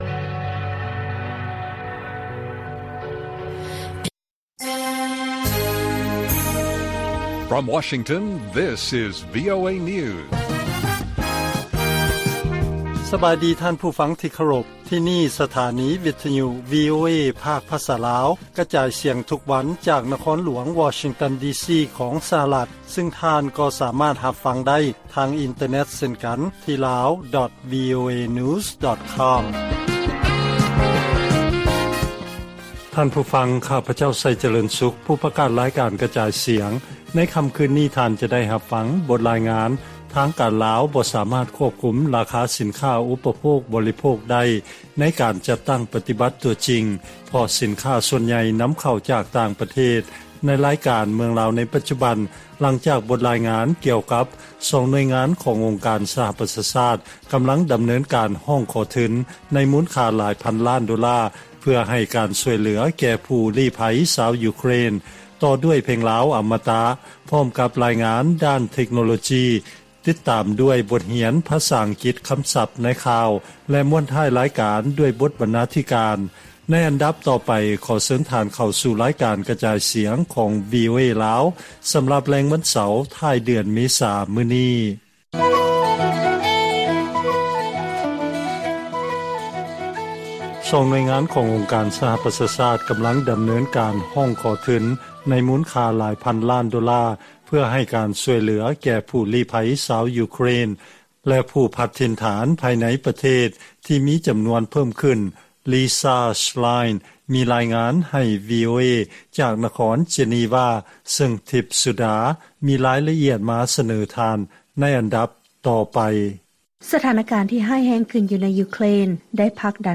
ລາຍການກະຈາຍສຽງຂອງວີໂອເອລາວ: ທາງການບໍ່ສາມາດຄວບຄຸມ ລາຄາສິນຄ້າ ອຸບປະໂພກບໍລິໂພກ